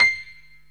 PIANO 0017.wav